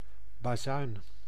Ääntäminen
Ääntäminen France: IPA: [tʁɔ̃.pɛt] Lyhenteet ja supistumat (musiikki) Tr.